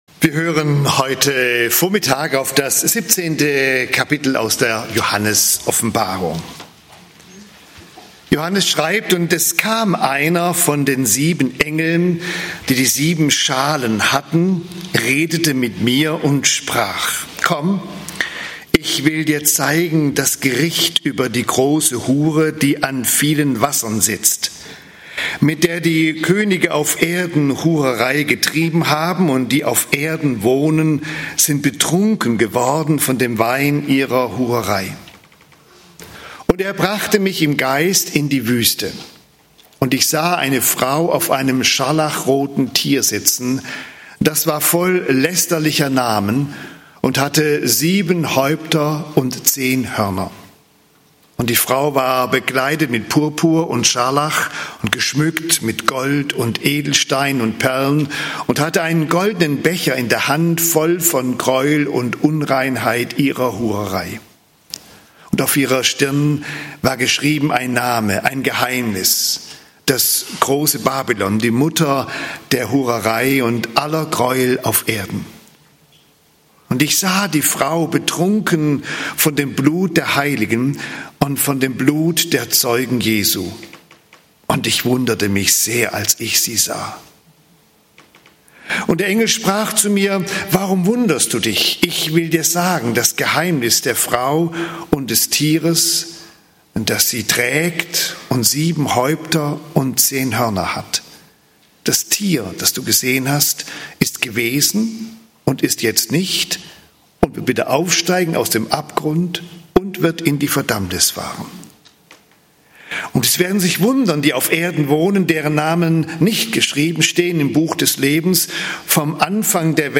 Weltenbrand (Offb. 17, 1-18) - Gottesdienst